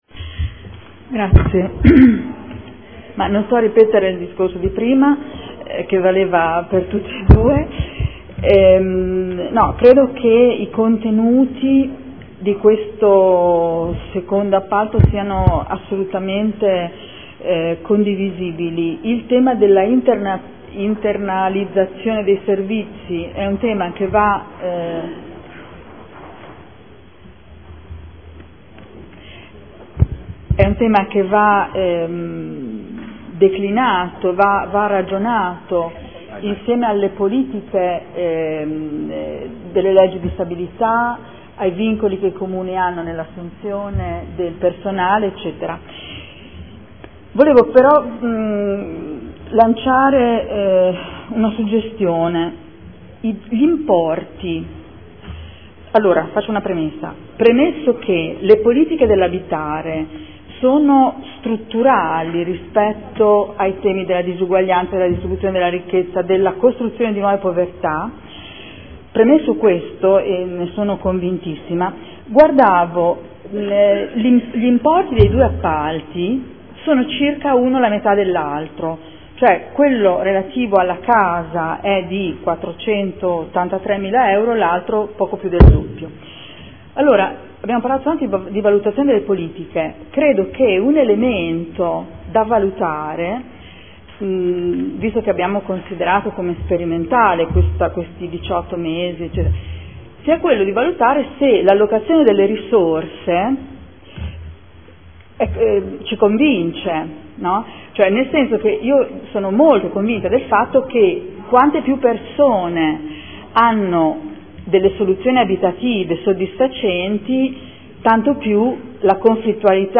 Adriana Querzè — Sito Audio Consiglio Comunale
Seduta del 11/12/2014 Linee guida per l’appalto per la gestione di attività e progetti orientati all’educazione all’abitare, mediazione condominiale e di vicinato – Periodo dall’1.4.2015 al 30.9.2016